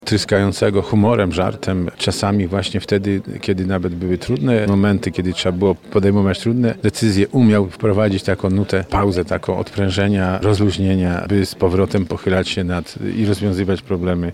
Rodzina, przyjaciele oraz politycy PSL złożyli kwiaty i zapalili znicze na grobie Edwarda Wojtasa na cmentarzu przy Lipowej w Lublinie.
– Pamiętam go jako wesołego człowieka – wspomina Edwarda Wojtasa marszałek Sławomir Sosnowski.